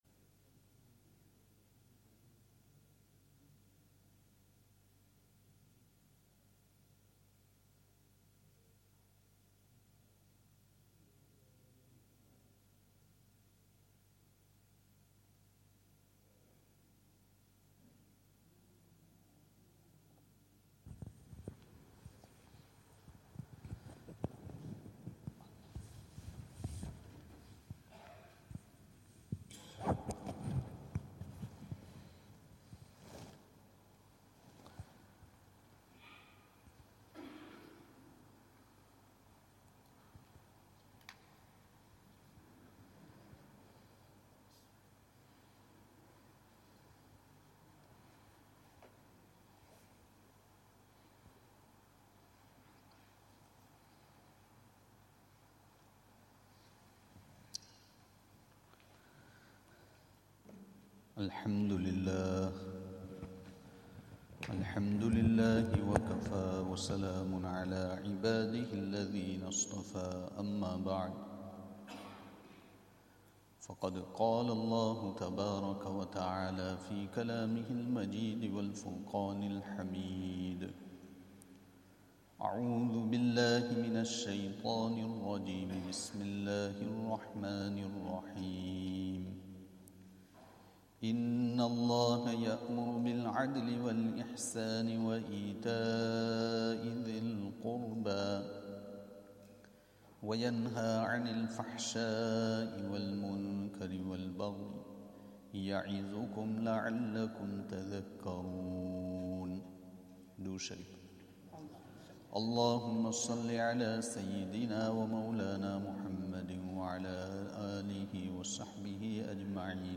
Zakariyya Jaam'e Masjid, Bolton